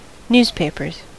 Ääntäminen
IPA: /blat/